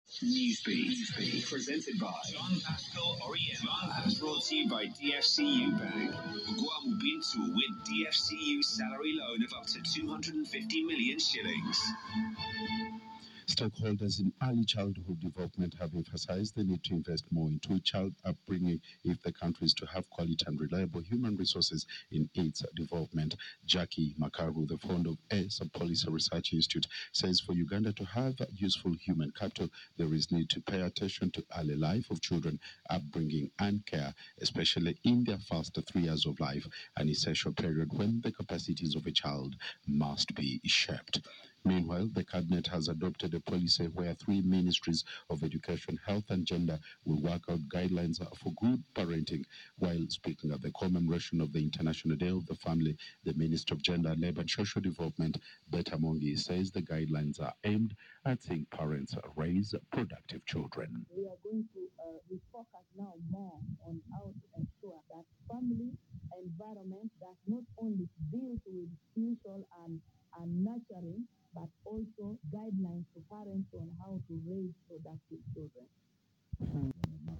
Audio News